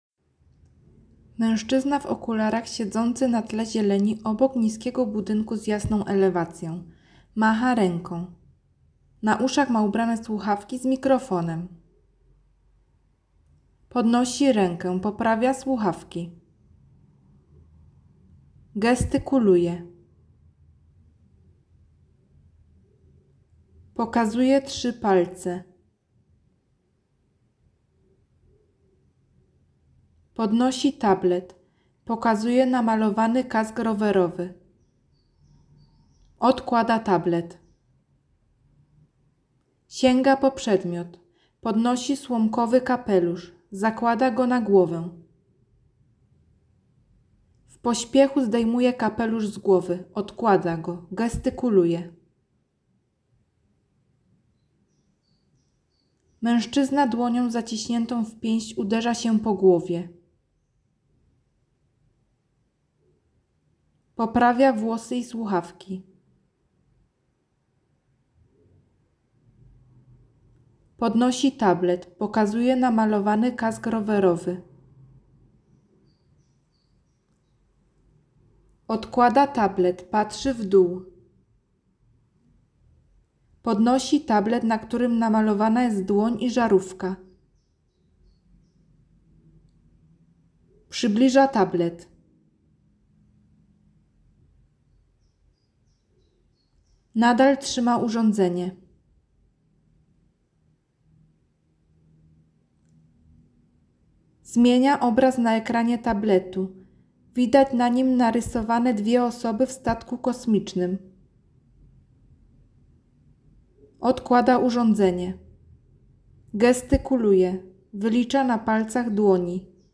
Całą akcję wspiera Jarosław Juszkiewicz, dziennikarz radiowy, lektor, którego głos jest znany z popularnej nawigacji.
Nagranie audio Audiodyskrypcja_Jaroslaw_Juszkiewicz.mp3